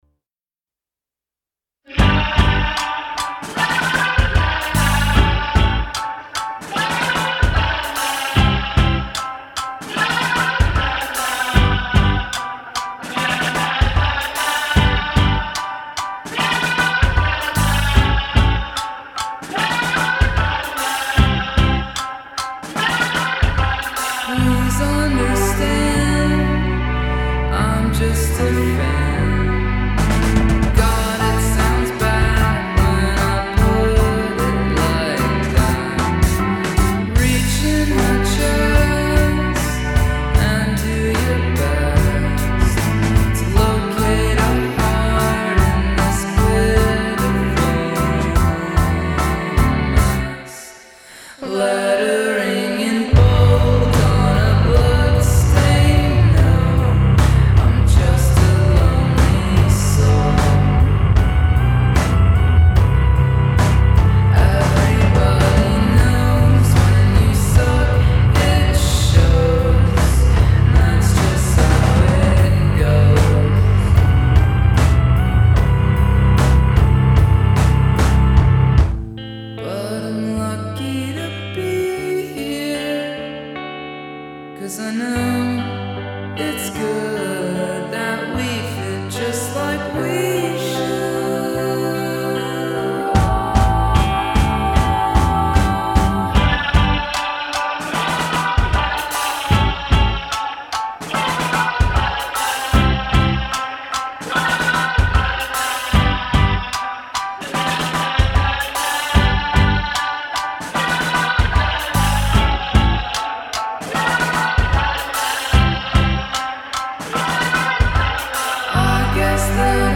trumpet
group vocals
group vocals, slide guitar, glock, cello, trumpet